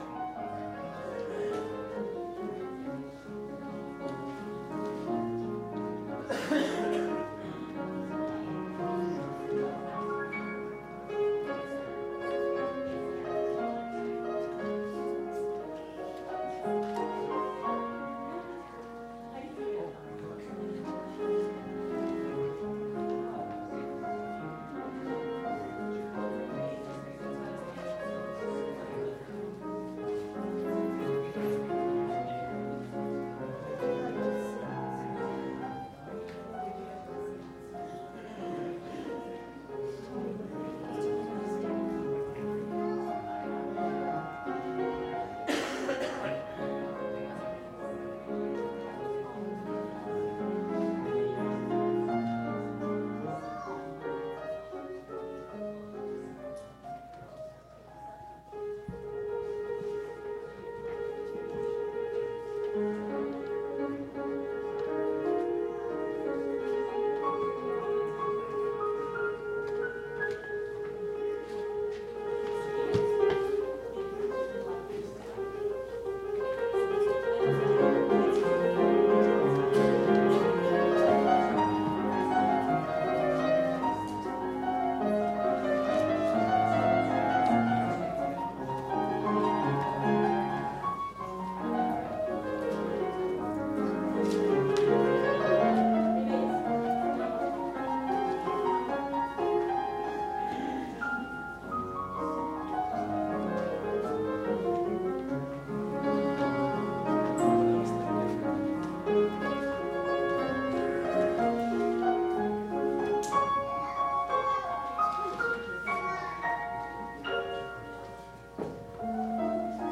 Calvin Christian Reformed Church Sermons
September 8 2019 The Gospel according to Hosea, Part 6: Knowledge ORDER OF WORSHIP Prelude Welcome/Announcements Time of Silence response: “Hear Our Prayer, O Lord” Call to Worship Song of Worship “Be Thou My Vision” God’s Greeting Prayer...